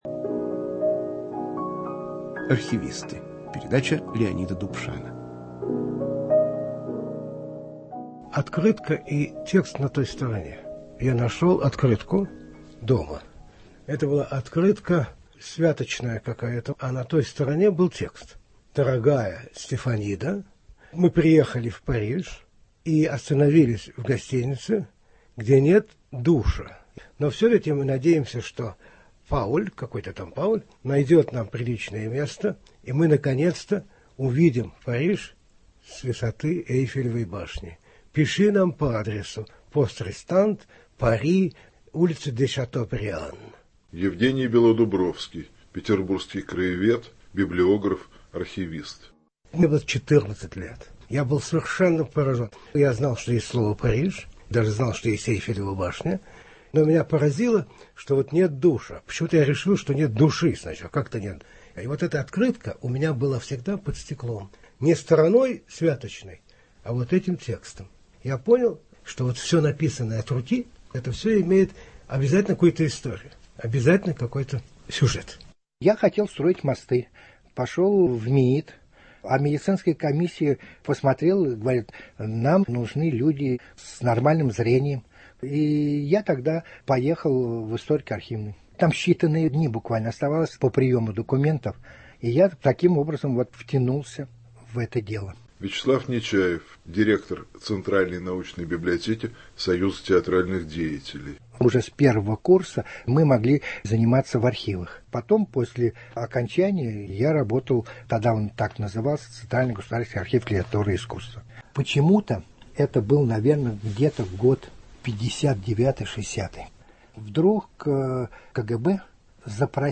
Российские архивисты рассказывают о своём призвании и о сопротивлении советской цензуре. В передаче звучат редкие записи А.Кручёных и В.Набокова.